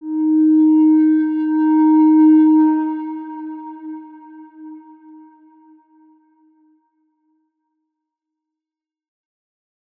X_Windwistle-D#3-pp.wav